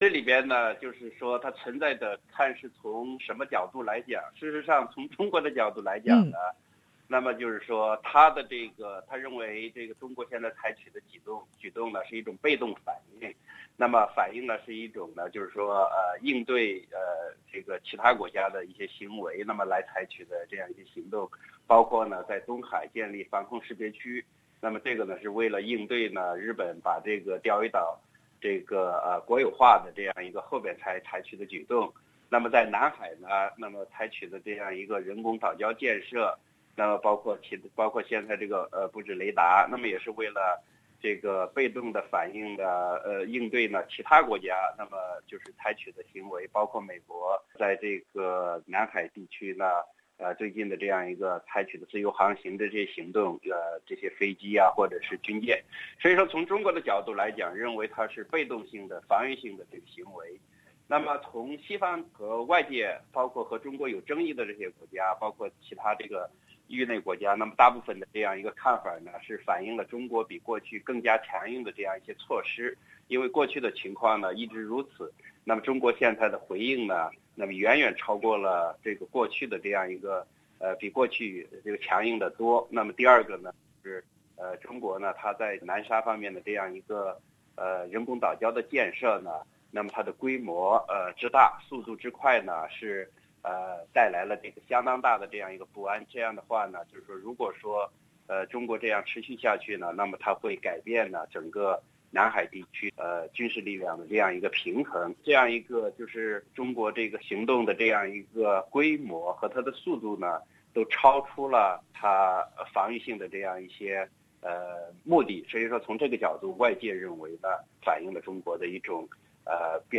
内容仅为嘉宾观点。